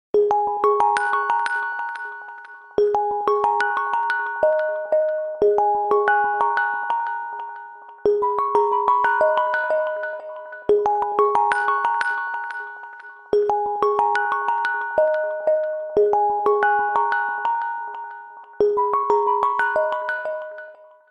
Electronic
без слов
колокольчики
звонкие
мелодия